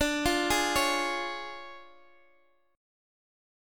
DmM7b5 chord